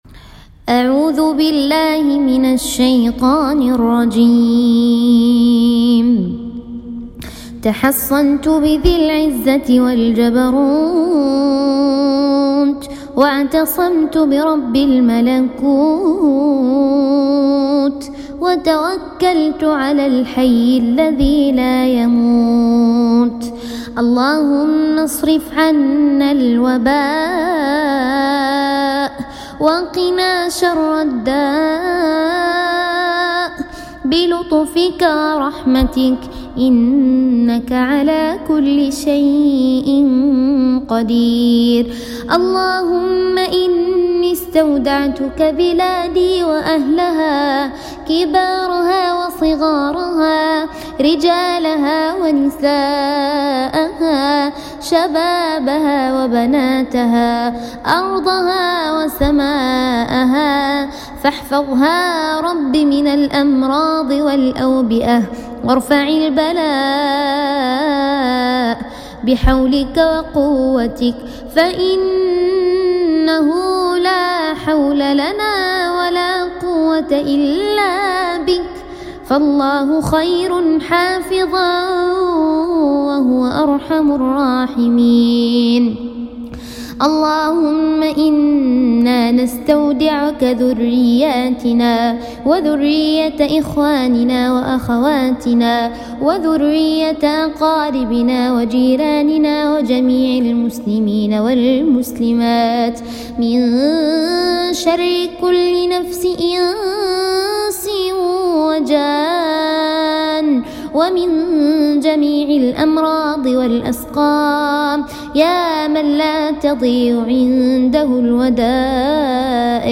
المحلضره الخامسه تصميم تجارب